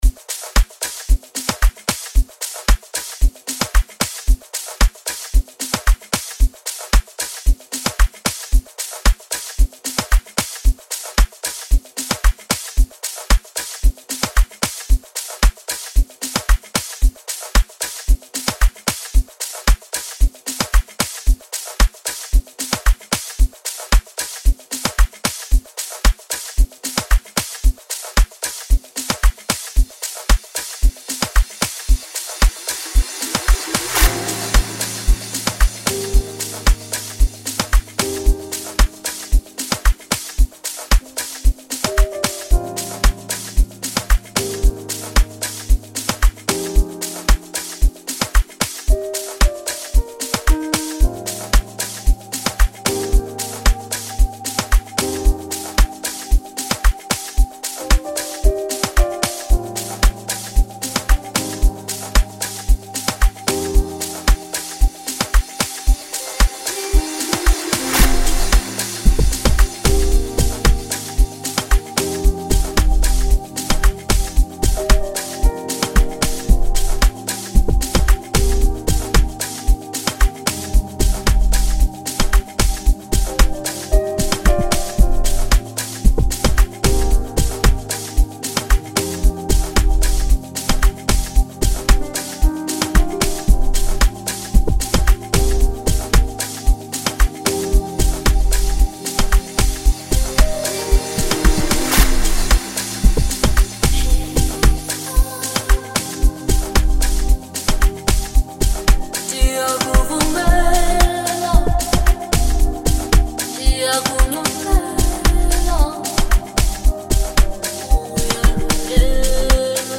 soulful vocals
emotive vocal performances
lush instrumentals